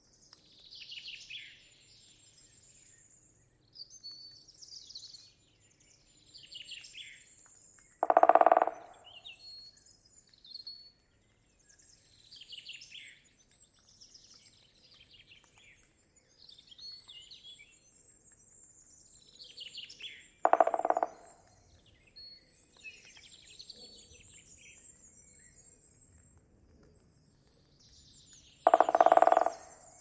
Photos de Pic épeiche - Mes Zoazos
picEpeiche.mp3